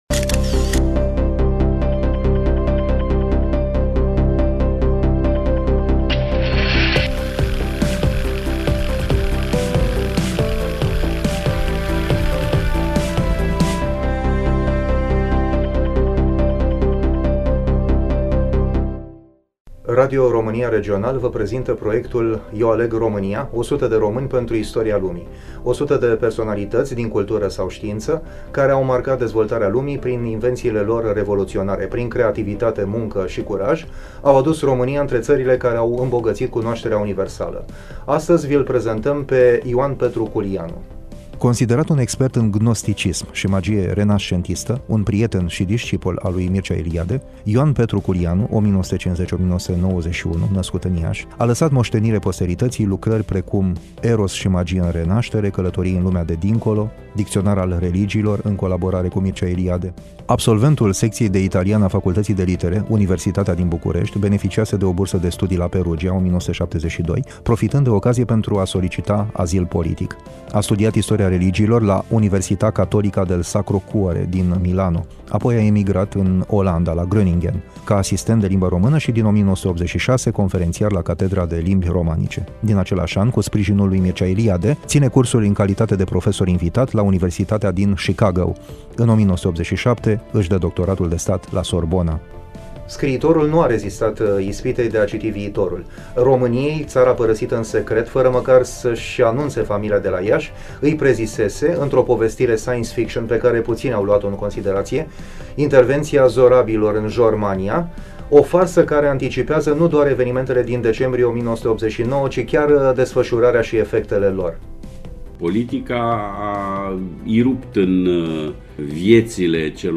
Studioul: RADIO ROMÂNIA IAȘI
Voice Over: